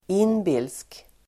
Uttal: [²'in:bil:sk]